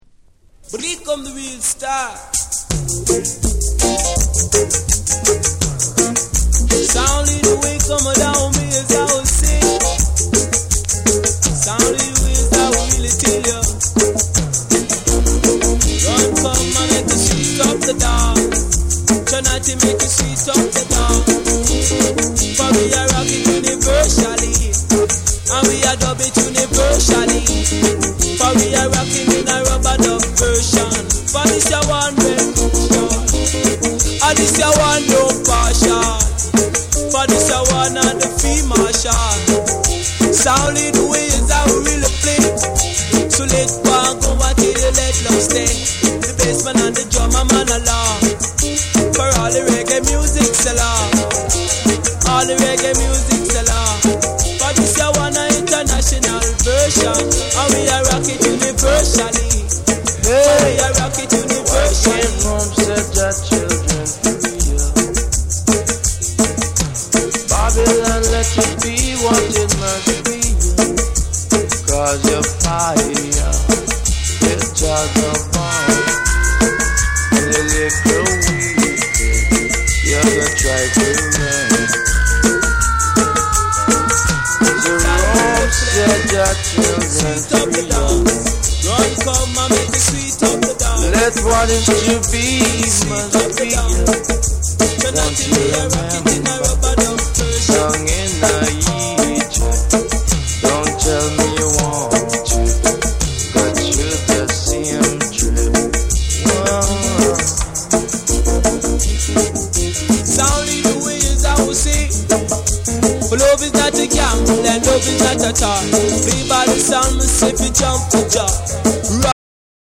ミニマルで奥行きあるプロダクションと、ルーツ〜ダンスホール前夜の空気をまとった独特の世界観は今聴いてもフレッシュ！